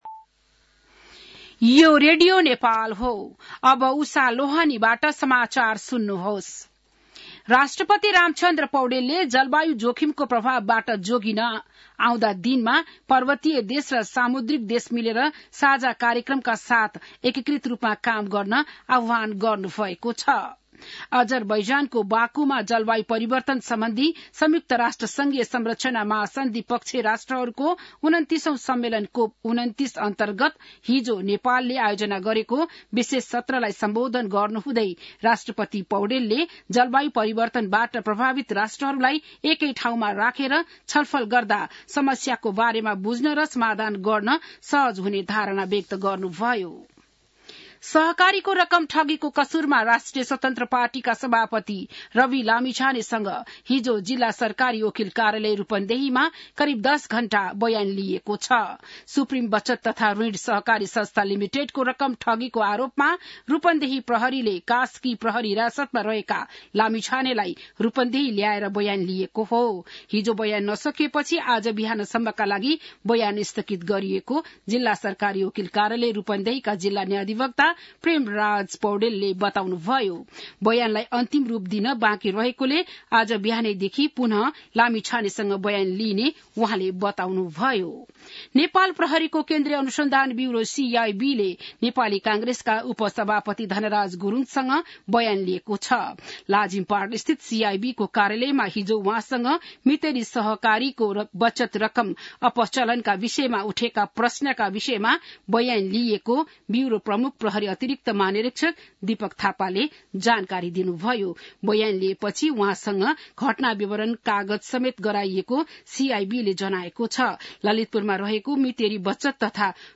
बिहान १० बजेको नेपाली समाचार : ३० कार्तिक , २०८१